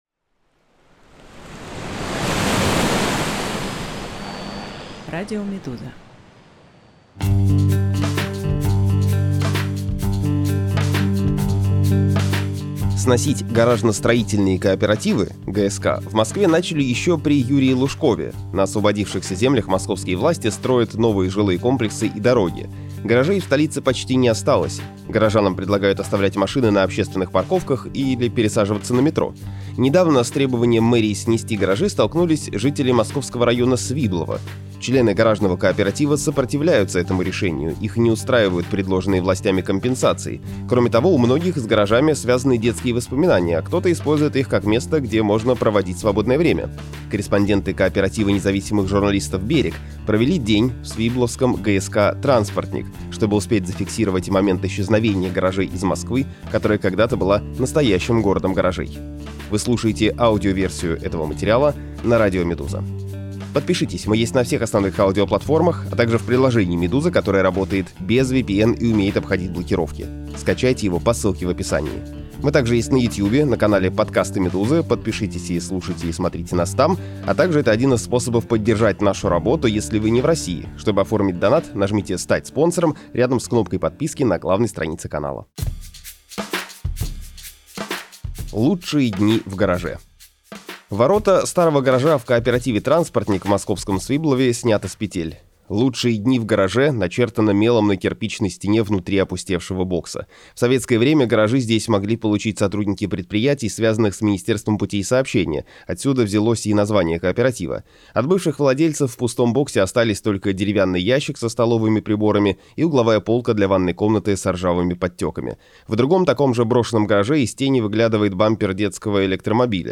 Аудиоверсия репортажа